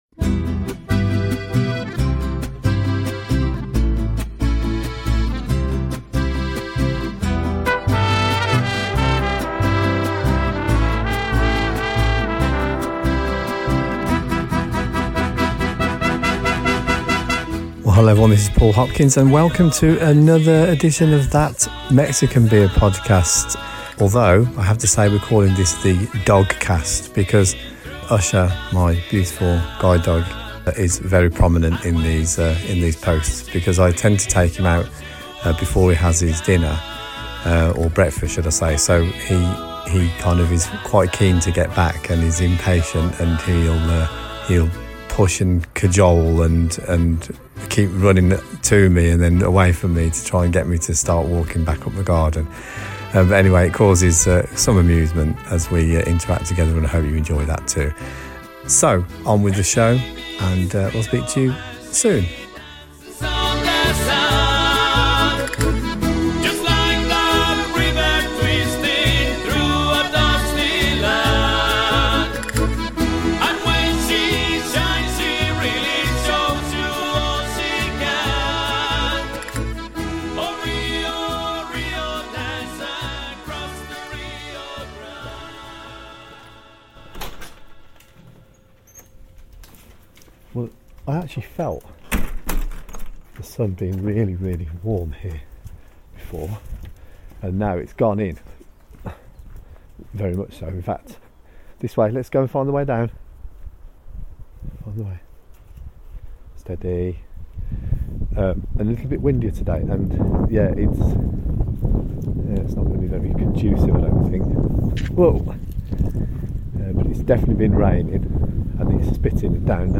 The constant is that Usher consistently interrupts the solitude as he bounds around the garden and pesters me for His breakf